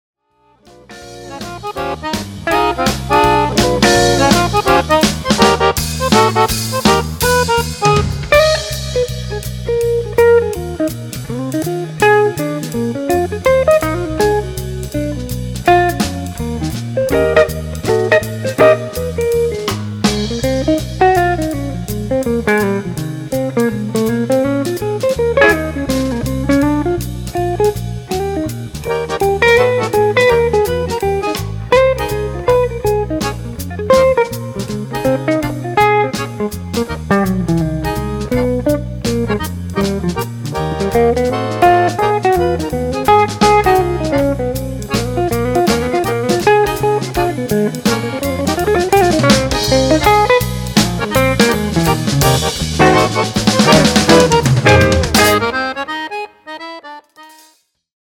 Инструментальный джаз